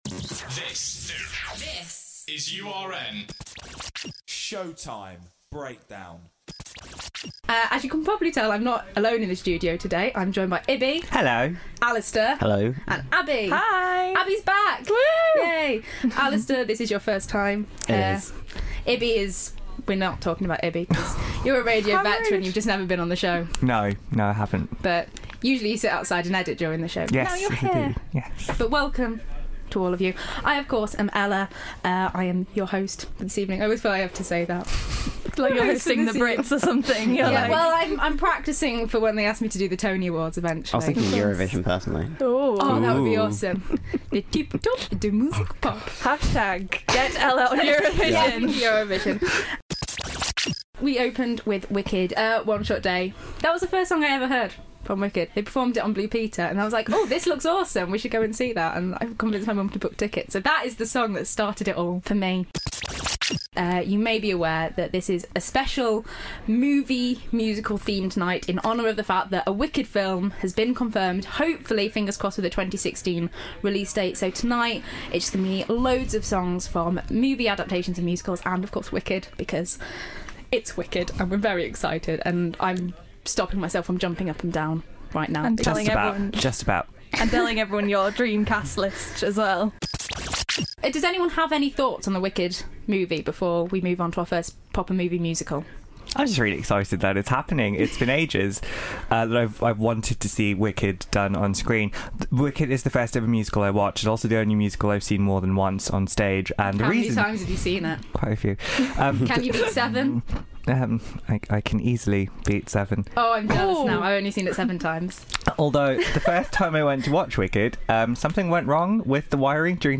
This week I'm joined by three fellow musical lovers to discuss movie adaptations of stage shows in light of the Wicked film that is hopefully coming out this year.